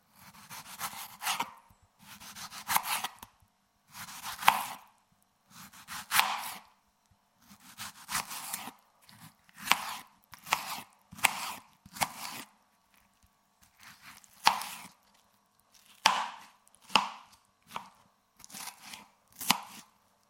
Звук нарезания помидора ножом для салата